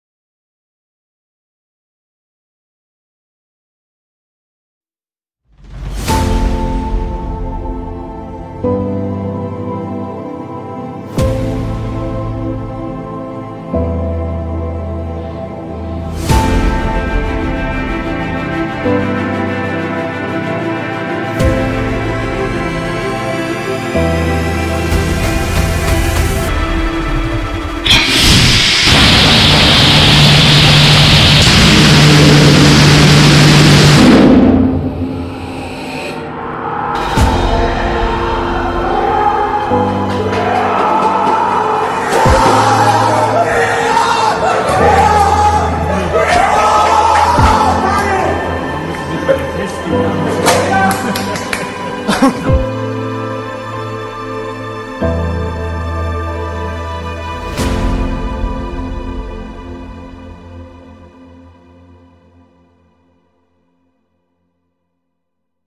For the first time, our sound effects free download By hyend_uni_stuttgart 0 Downloads 13 months ago 65 seconds hyend_uni_stuttgart Sound Effects About For the first time, our Mp3 Sound Effect For the first time, our student team has successfully ignited our battleship engine, marking a significant milestone in our new liquid propulsion project.
Our engine uses ethanol and nitrous oxide as propellants. We achieved ignition by firing a small solid model rocket motor through the engine's throat.